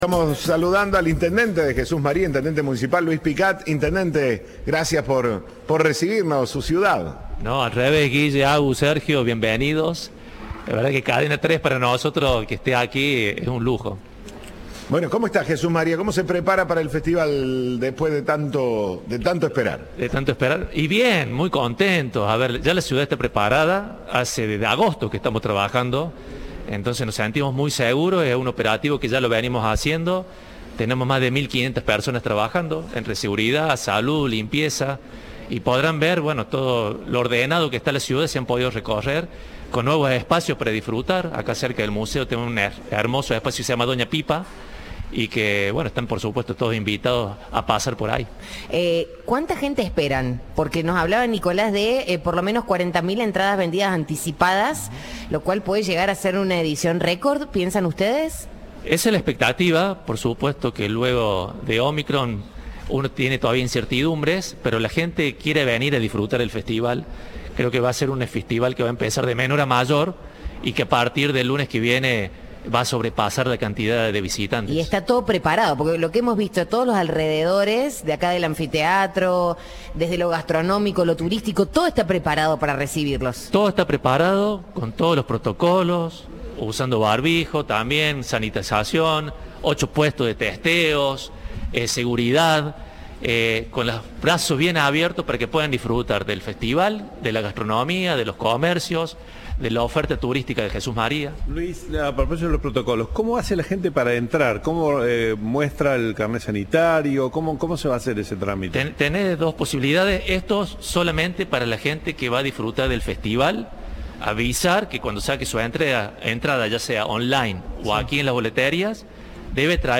Entrevista de Siempre Juntos.